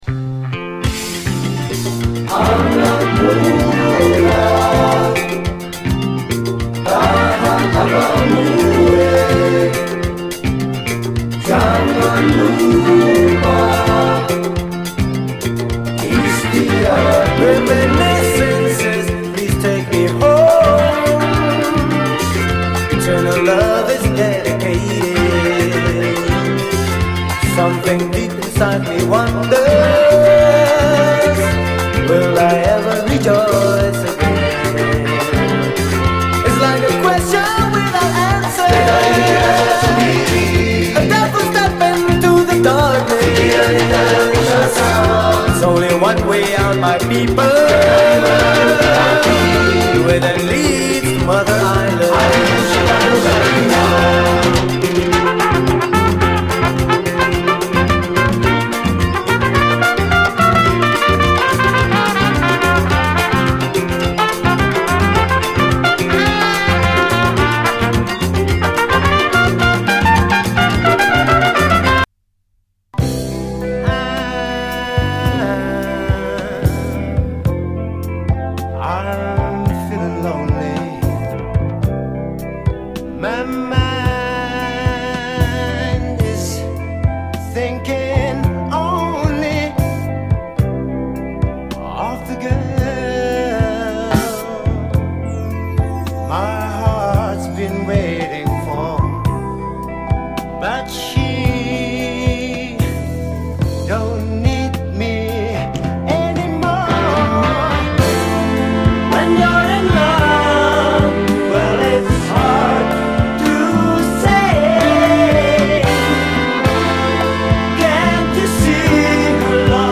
SOUL, 70's ROCK, 70's～ SOUL, LATIN, ROCK
トロけるような夕暮れメロウ・グルーヴ